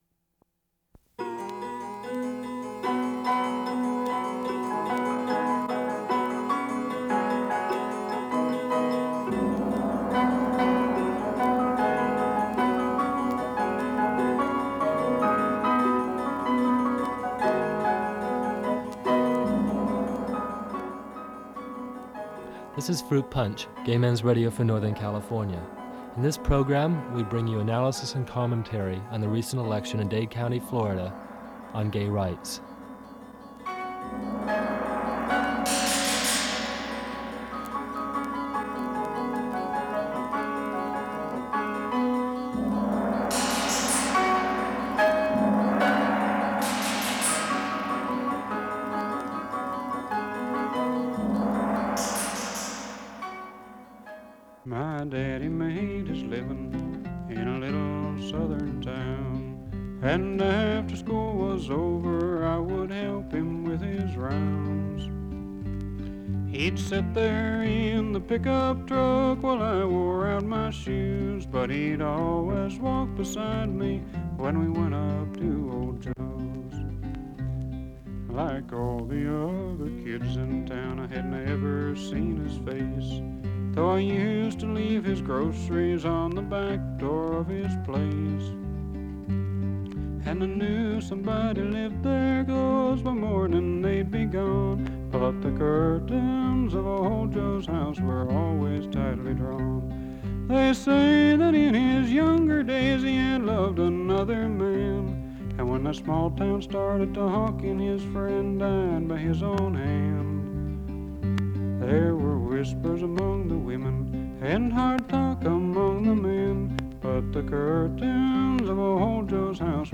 – Fruit Punch – Orange Tuesday Analysis – Northern California Gay Men’s Radio Collective – June 15, 1977 –